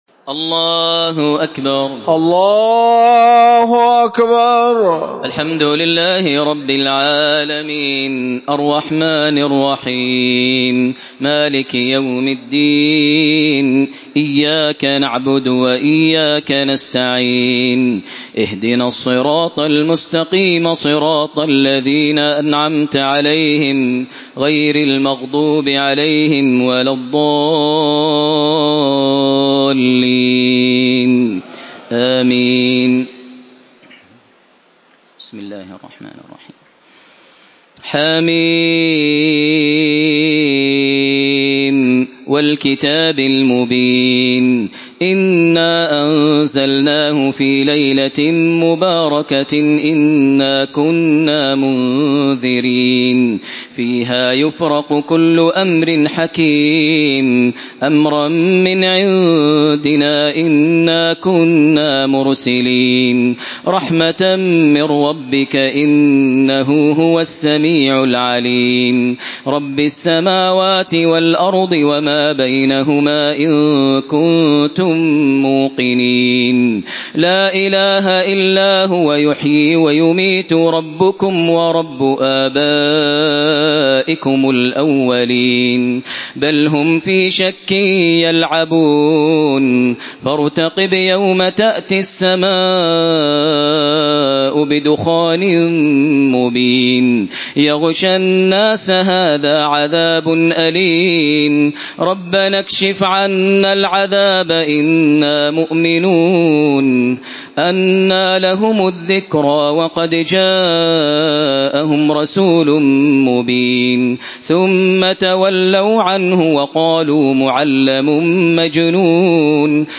Surah Ad-Dukhan MP3 by Makkah Taraweeh 1432 in Hafs An Asim narration.